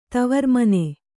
♪ tavarmane